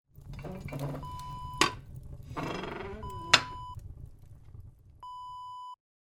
Fireplace door close sound effect .wav #2
Description: The sound of fireplace doors being closed
Properties: 48.000 kHz 24-bit Stereo
A beep sound is embedded in the audio preview file but it is not present in the high resolution downloadable wav file.
Keywords: fireplace, door, metal, hatch, close, closing, fire
fireplace-door-close-preview-2.mp3